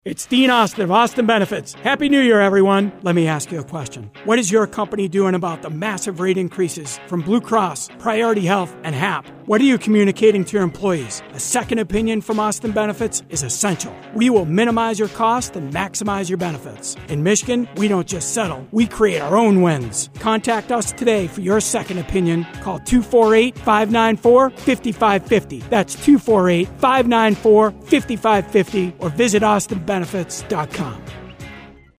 Detroit Lions Radio Network Commercial